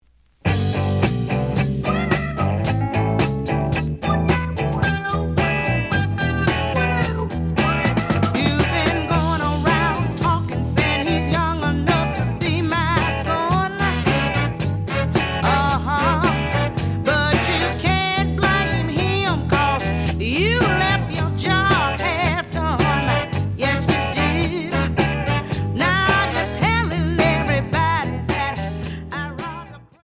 The audio sample is recorded from the actual item.
●Genre: Funk, 70's Funk
Slight noise on A side.